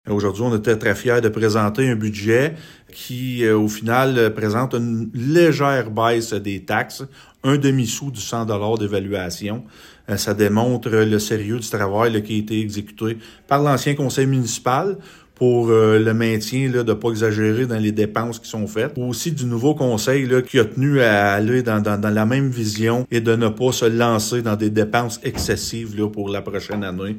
Selon le maire de Gracefield, Mathieu Caron, l’administration a déployé des efforts soutenus pour livrer à temps un budget 2026 complet, transparent, fidèle à la réalité actuelle, avec une prévision réaliste :